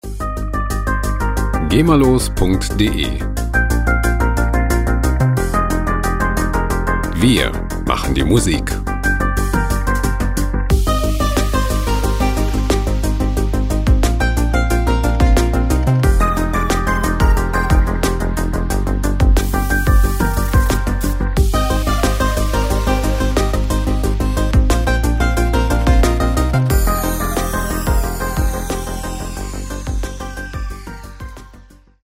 • Synth Pop aus Japan